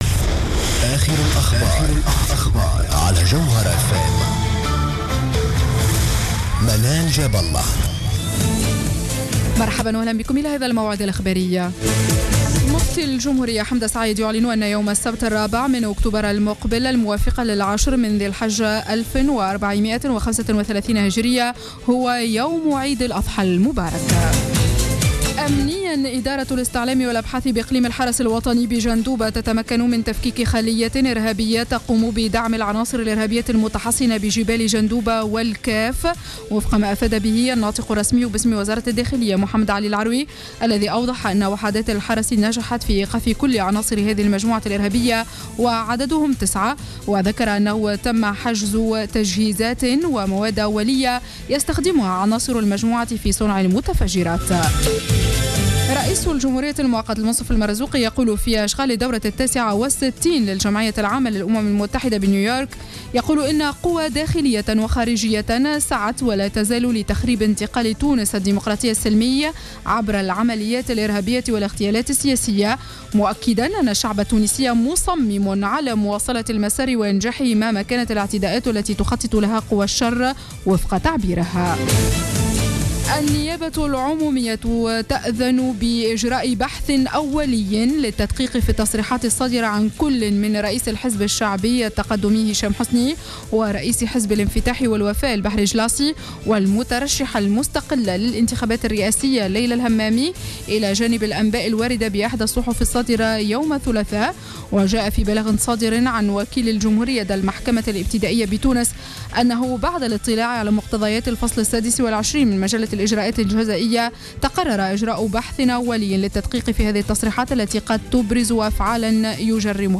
نشرة أخبار منتصف الليل ليوم الجمعة 26-09-14